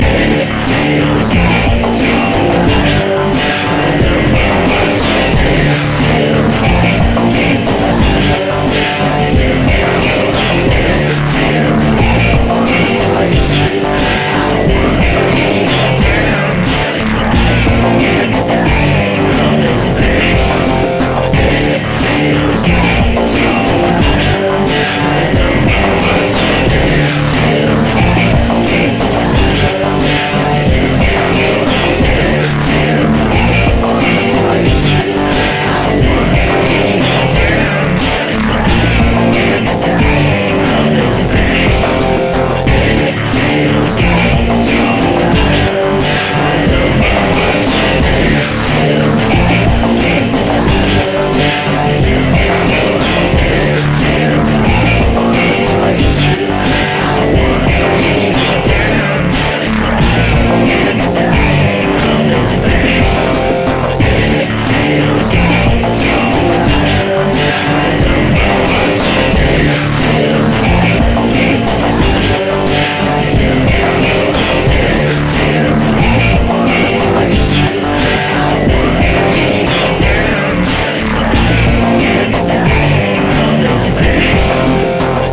FYI - ALL the synths and drums are the G Force impOSCar